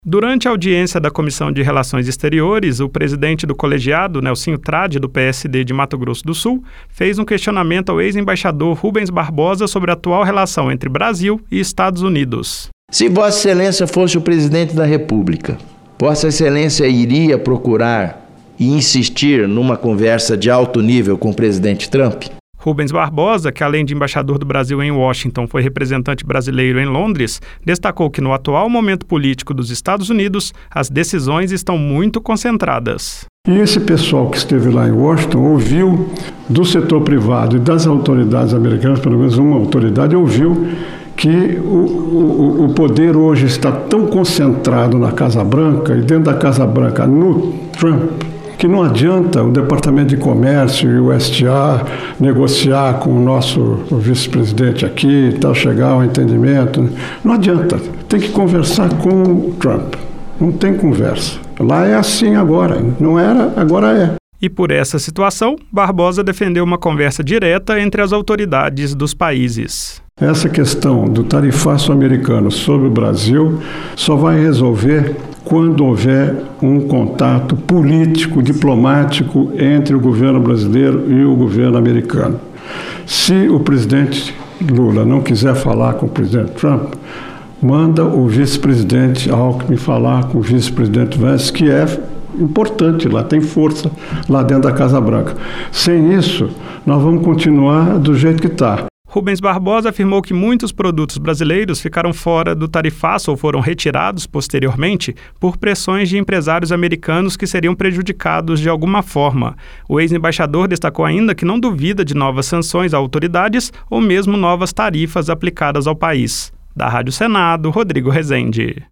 Ao responder a um questionamento do presidente da Comissão de Relações Exteriores (CRE), Nelsinho Trad (PSD-MS), o ex-embaixador Rubens Barbosa afirmou nesta terça-feira (23) que presidentes e vices do Brasil e dos Estados Unidos deveriam conversar para resolver a questão do tarifaço. Barbosa, que foi embaixador em Washington, destacou que as decisões durante o governo de Donald Trump estão concentradas na Casa Branca, o que dá pouca eficácia a conversas feitas em outros espaços ou com outros representantes do governo norte-americano.